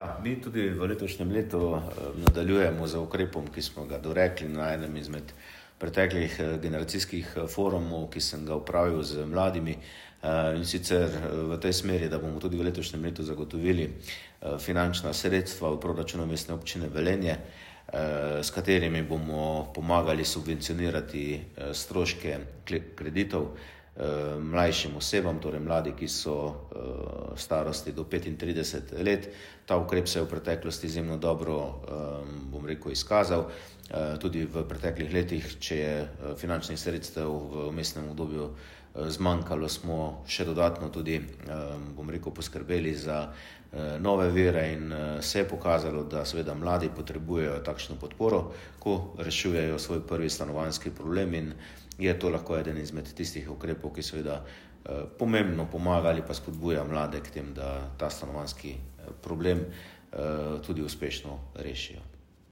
izjavo župana Mestne občine Velenje Petra Dermola.